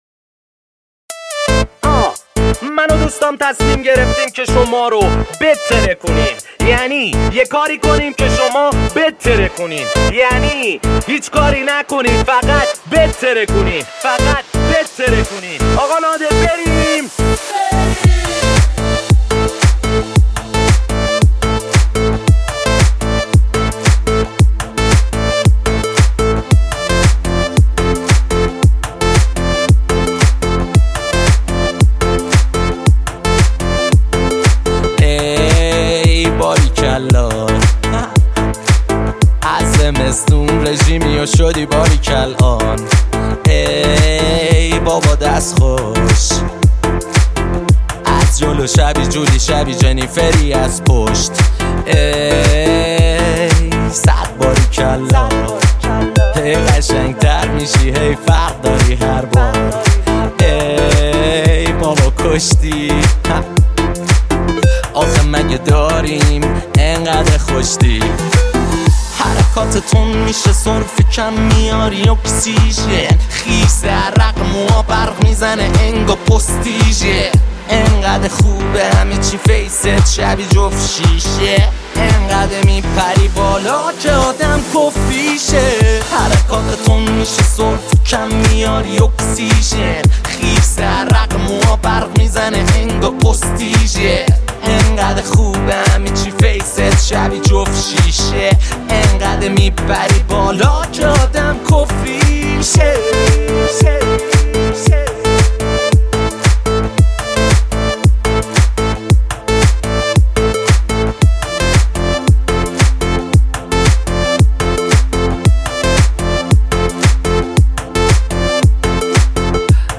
اهنگ غمگین, اهنگ رپ